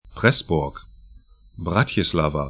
Pressburg 'prɛsbʊrk Bratislava 'bratjɪslava sk Stadt / town 48°09'N, 17°07'E